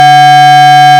As you can see, we have shifted the spectrum, but we have lost the typical relation between partials such that they are no longer an integer multiple of the fundamental.